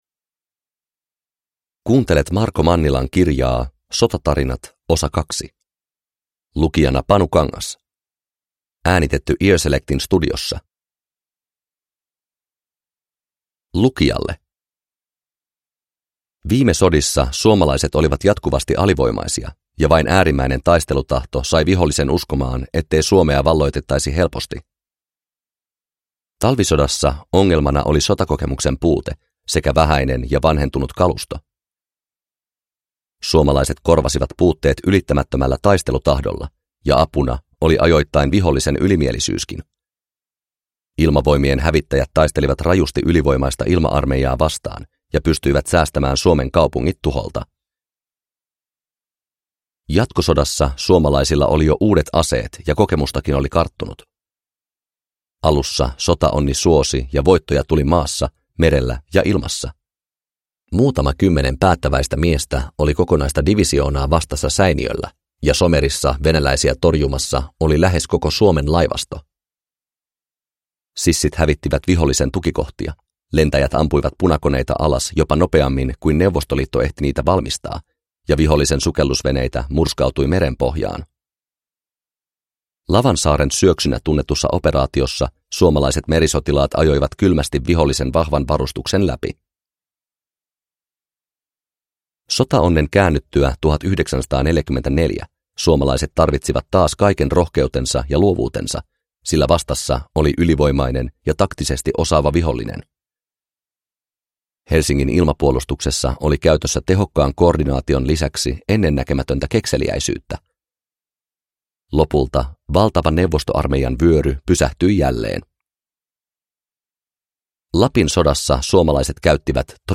Sotatarinat 2 – Ljudbok – Laddas ner